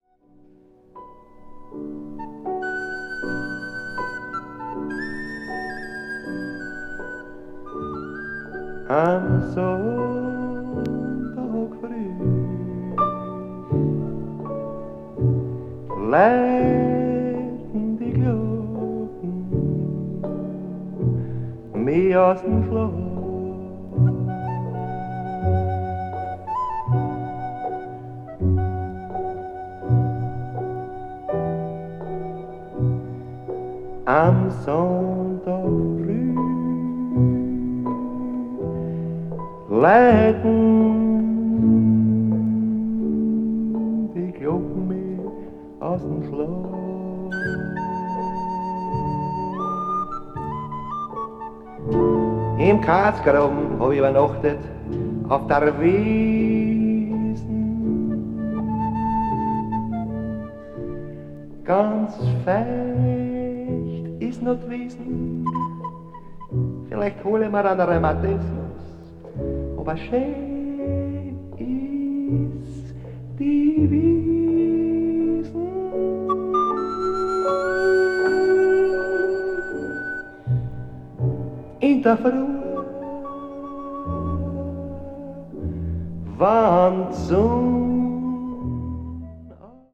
media : EX/EX,EX/EX(わずかにチリノイズが入る箇所あり)
C1はまるでブラジル音楽のようにも聞こえるところが不思議です。